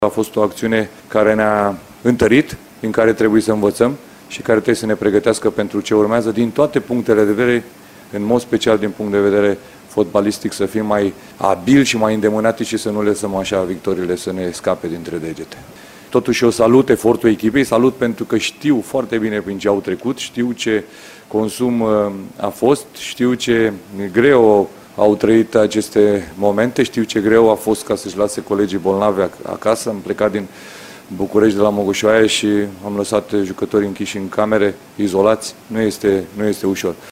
Iordănescu jr. a încercat unele concluzii ”la cald” după cele două meciuri test de la începutul mandatului său, cu Grecia și Israel:
Edi-Iordanescu-concluzii-ale-primelor-jocuri-ca-selectioner.mp3